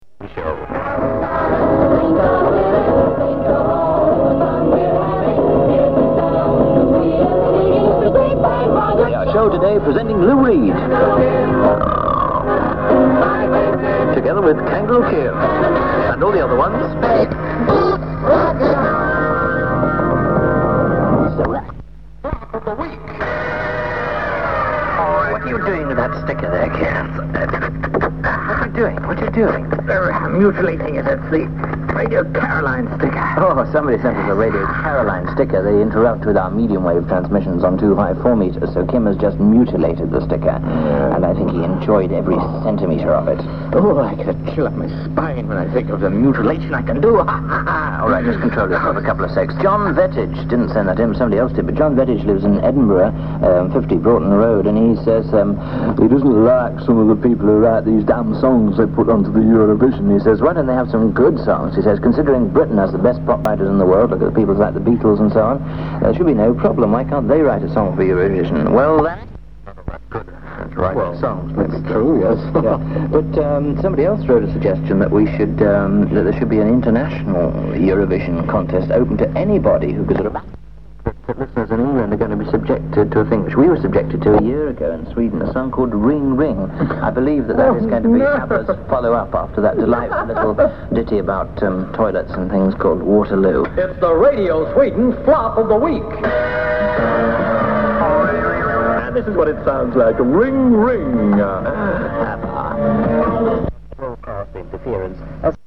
As usual when browsing archives, I found a few more recordings that may or may not be of interest to dxers, recorded here in the early 70's with a very small cassette machine.